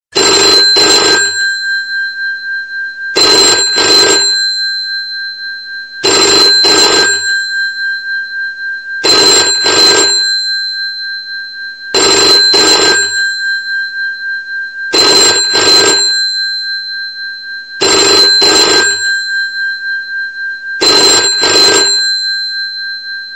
Kategorien: Telefon